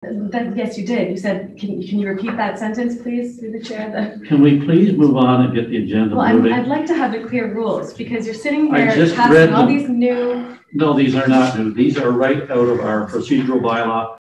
Monday night it took several minutes just to get the agenda approved as councillor Sari Watson, Mayor Bob Mullin and staff exchanged opinions on Points of Order and Points of Privilege.
Stirling-Rawdon-council-squabble.mp3